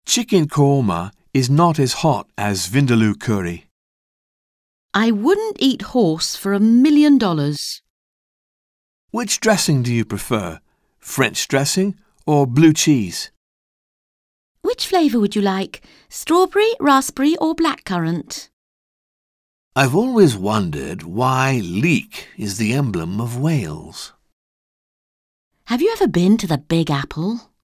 Un peu de conversation - Les aliments et les ingrédients